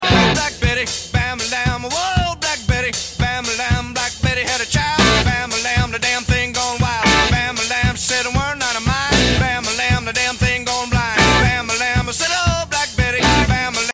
Cool machine